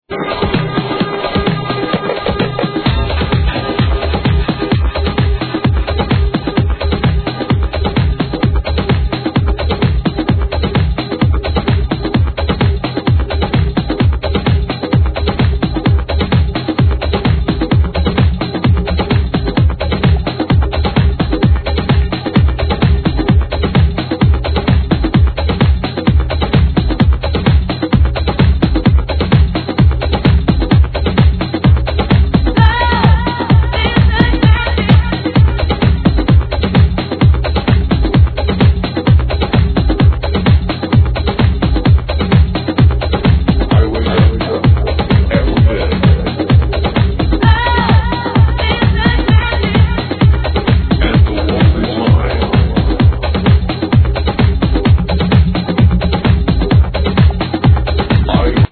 -- Please Help ID This Tribal House Tune from 03 - TranceAddict Forums